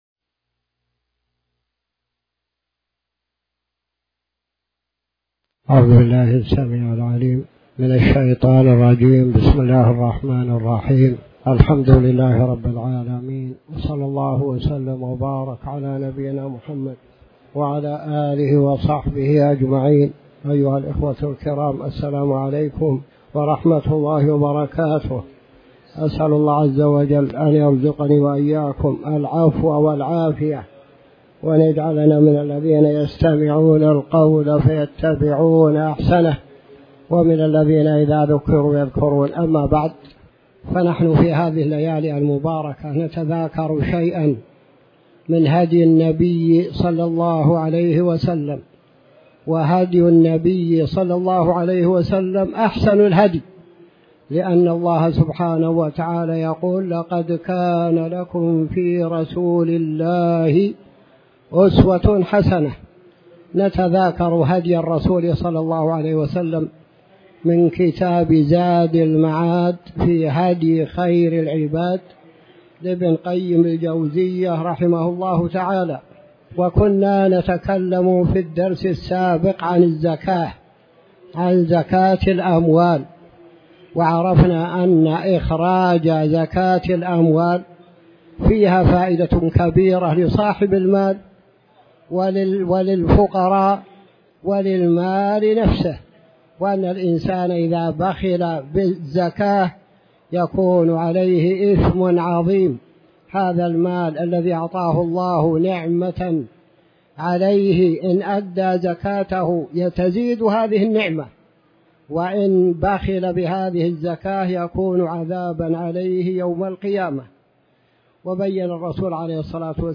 تاريخ النشر ١٣ محرم ١٤٤٠ هـ المكان: المسجد الحرام الشيخ